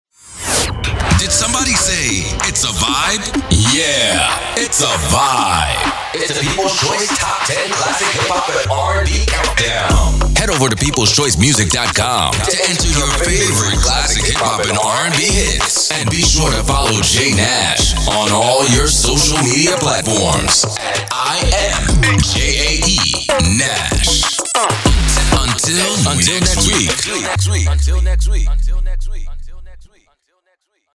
ITS A VIBE MUSIC PODCAST OUTRO
ITS-A-VIBE-Outro.mp3